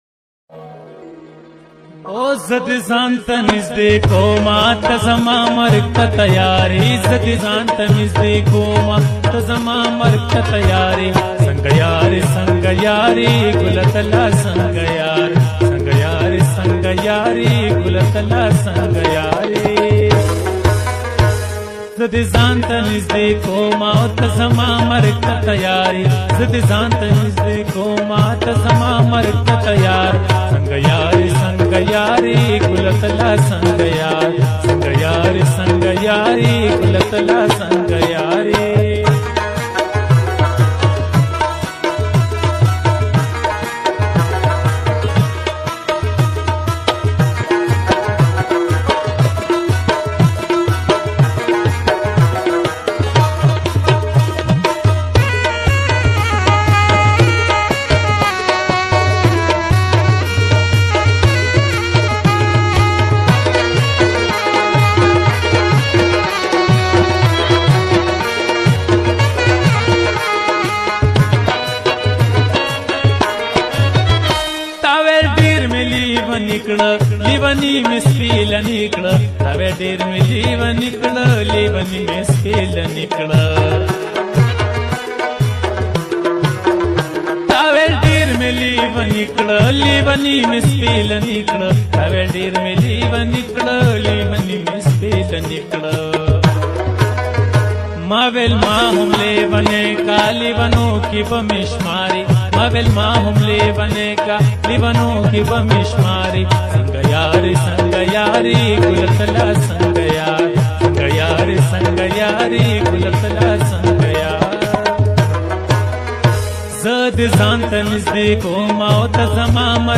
𝙨𝙡𝙤𝙬𝙚𝙙+𝙍𝙚𝙫𝙚𝙧𝙗 𝙨𝙖𝙙 𝙨𝙤𝙣𝙜